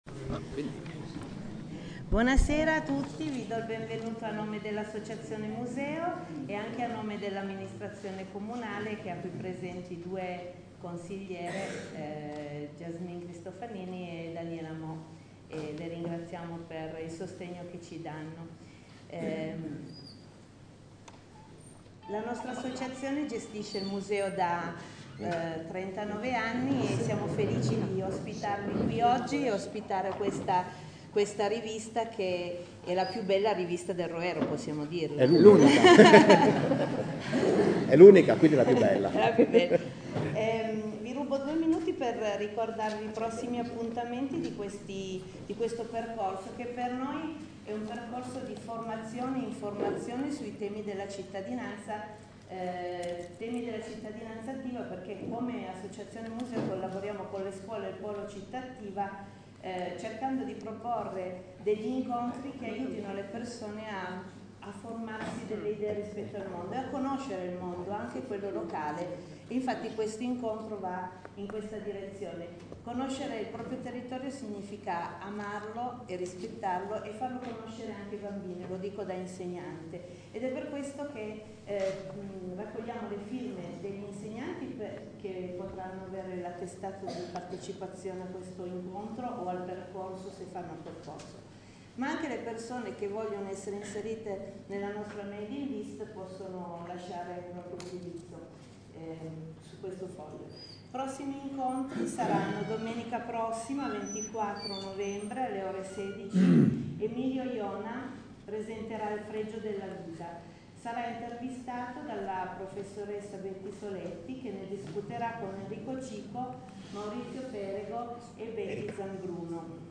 REGISTRAZIONE DELL’INCONTRO IN FORMATO MP3